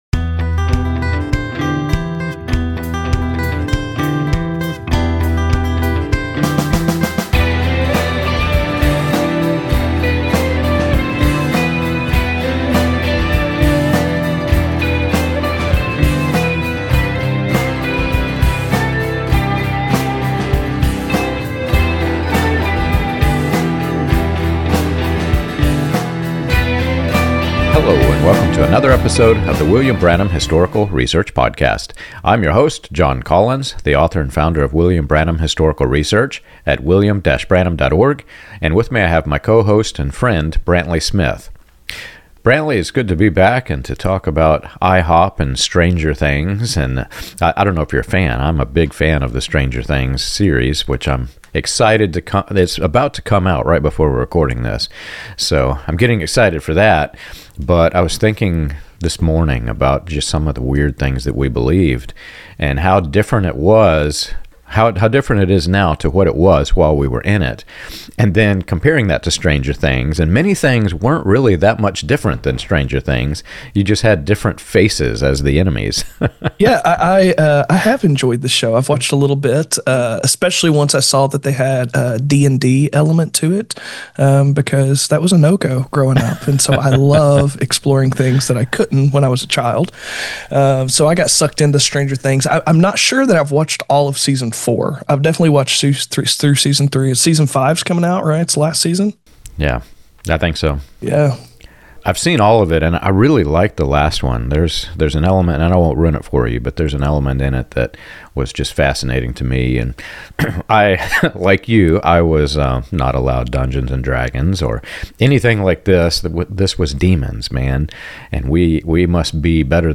They also explore how money, secrecy, elitism, and militarized theology feed high-control movements—from Branhamism to IHOPKC to YWAM—shaping everything from personal identity to political attitudes. The conversation connects historical roots, cult dynamics, and lived experience to show how ordinary people can get swept into extraordinary (and sometimes absurd) belief systems, and what it takes to walk away.